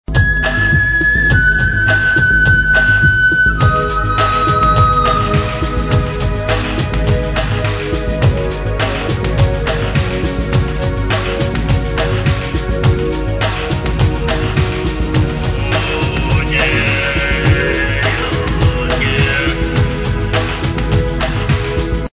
Saxophone,
Sitar,